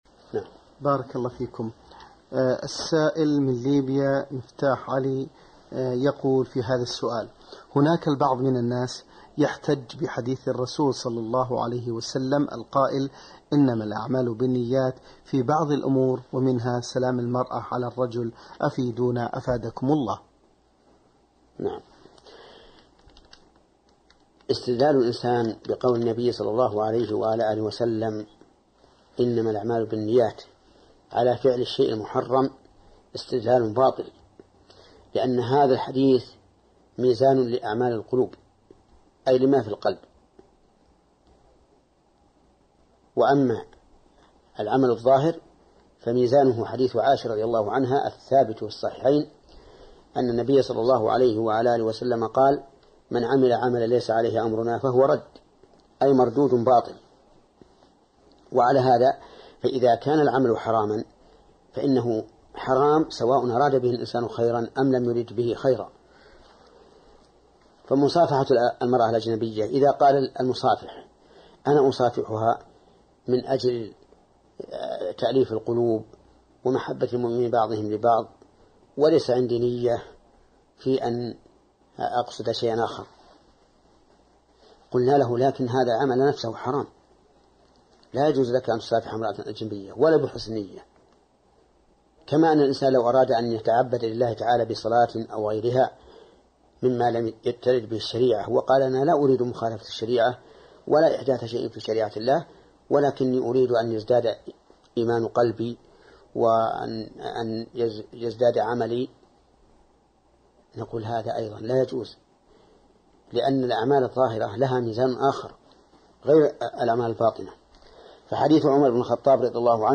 الفتاوى  |  تخصيص شهر رجب بعبادة | محمد بن صالح العثيمين